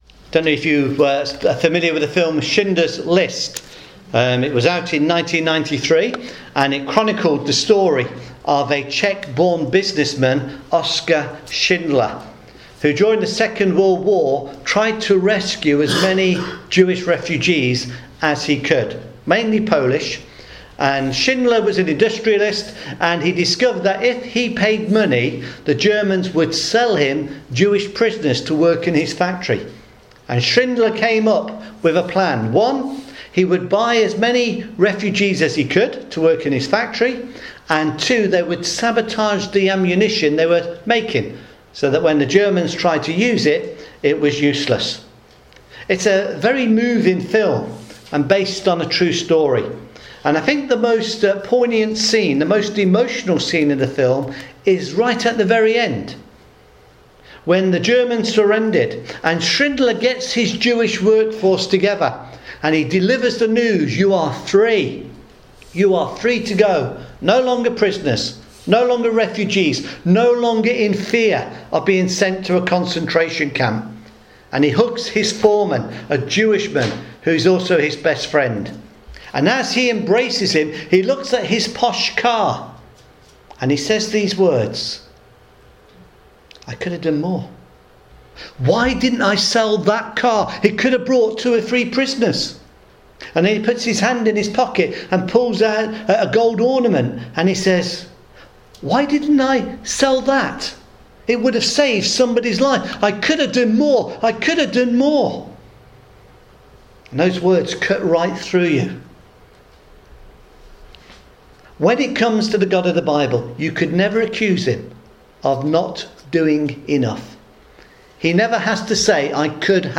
‘I believe’ (Gospel Sermon)